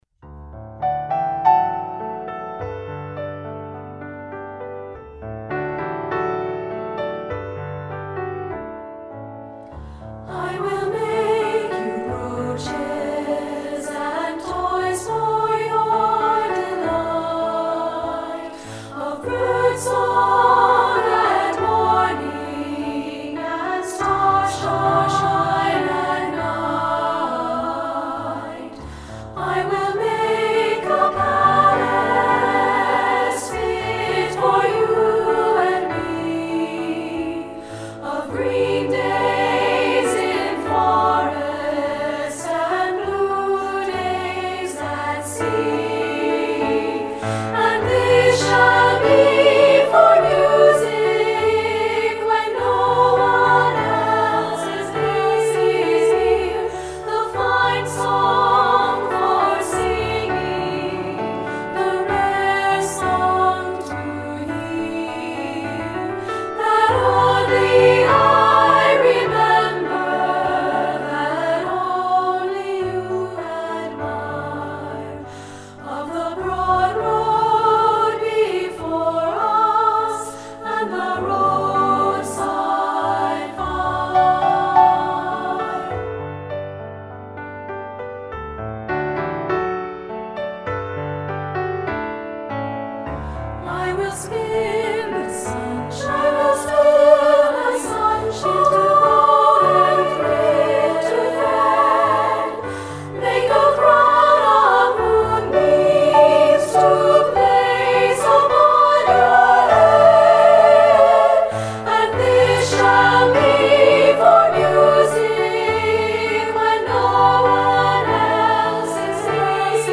Instrumentation: choir (2-Part / SSA)
secular choral